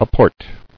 [a·port]